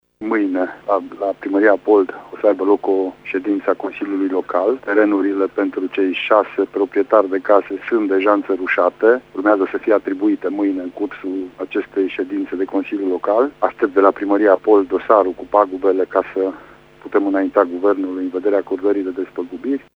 De asemenea, Goga a spus că mâine consiliul local al comunei mureşene va decide alocarea de teren pentru cele şase familii afectate: